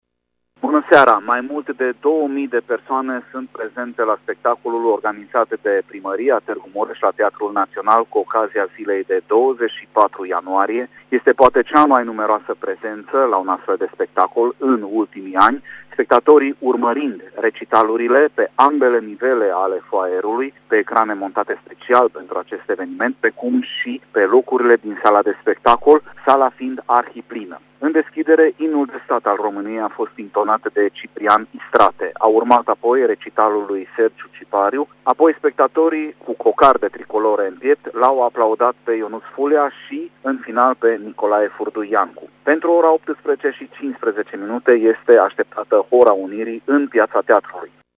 Sub genericul „Unirea este România!” la Teatrul Naţional Tîrgu Mureş are loc la această oră un spectacol folcloric extraordinar organizat de Primăria Tîrgu Mureş, la aniversarea a 159 de ani de la Unirea Principatelor Române.